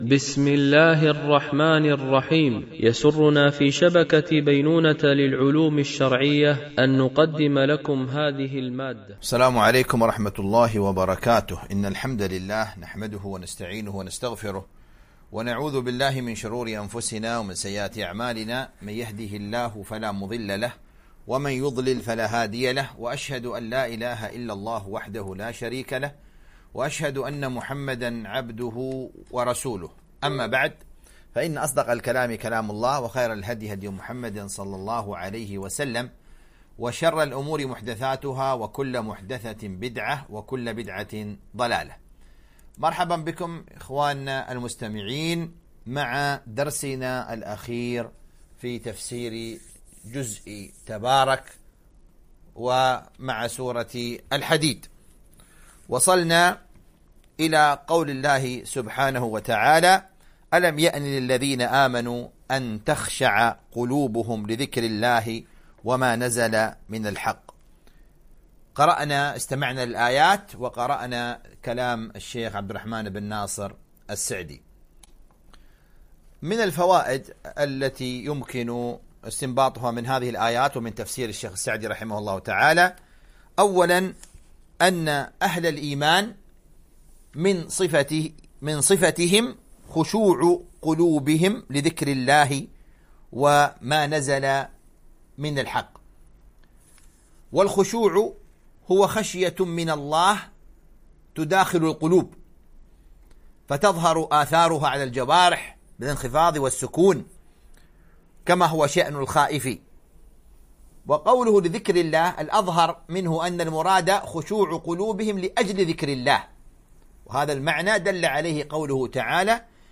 تفسير جزء الذاريات والأحقاف ـ الدرس 31 ( سورة الحديد )